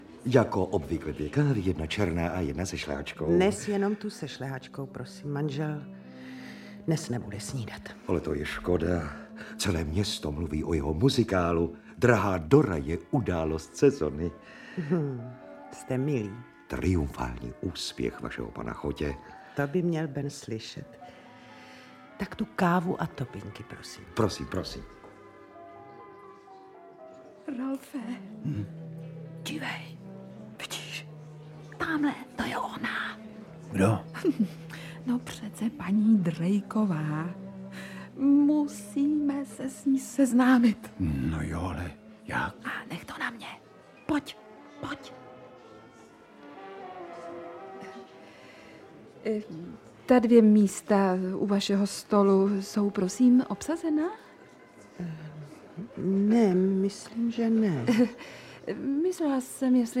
Audiobook
Read: Vladimír Brabec